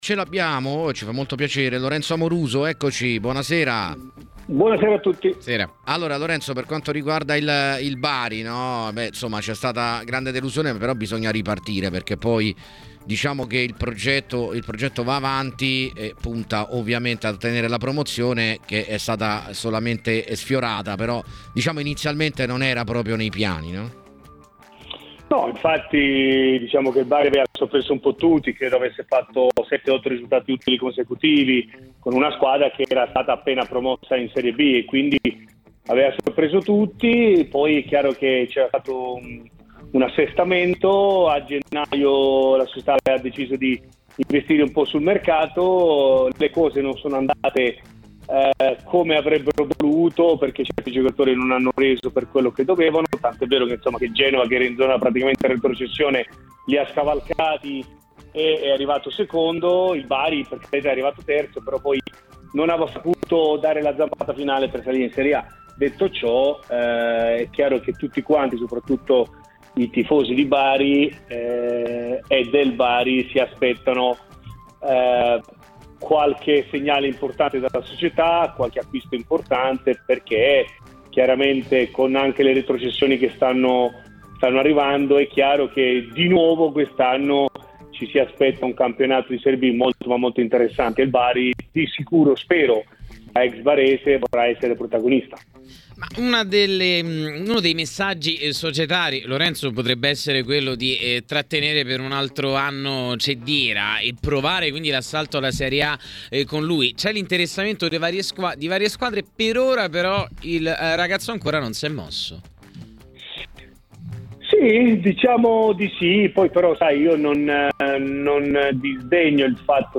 Lorenzo Amoruso, intervistato durante Piazza Affari su TMW Radio, ha commentato la finale playoff tra Bari Cagliari.